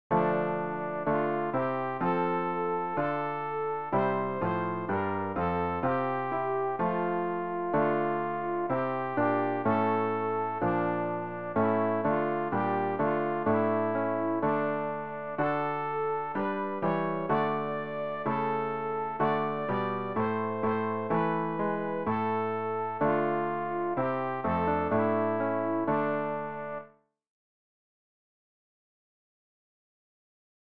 sopran-rg-320-dank-sei-dir-vater-fuer-das-ewge-leben.mp3